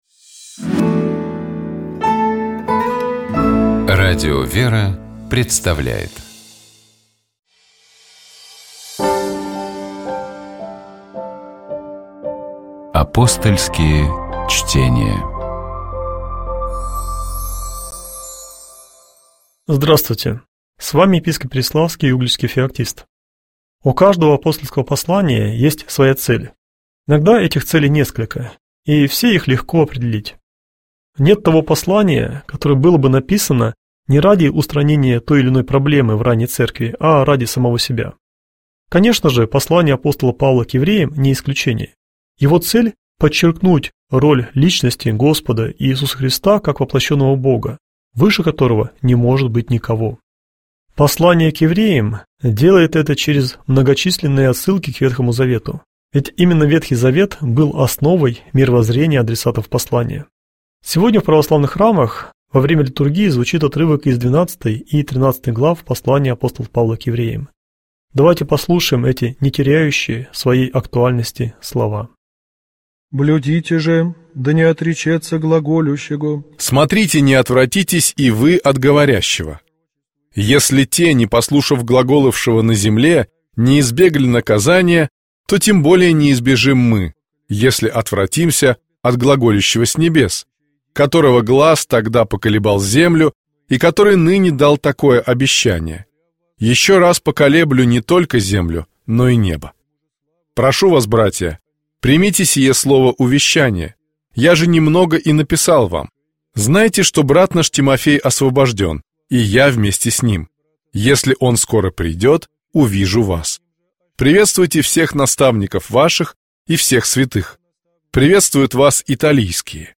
Мы беседовали